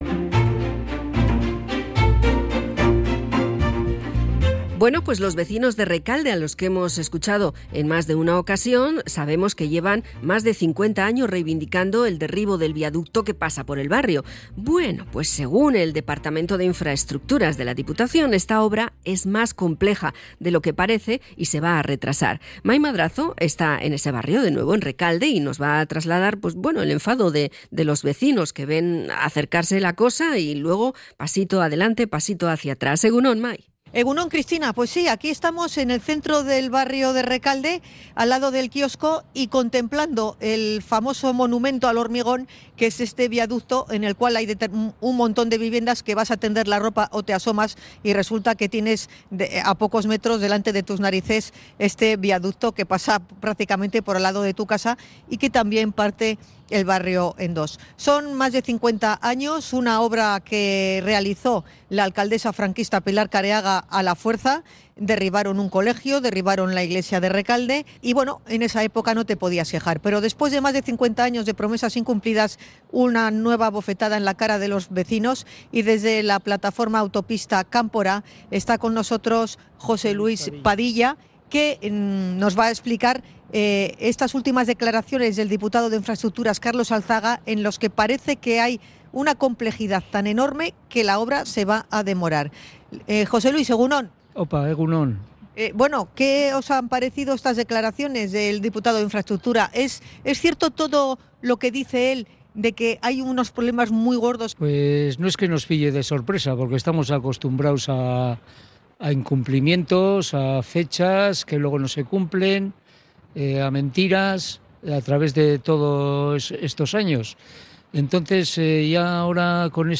miembro de la plataforma Autopista Kanpora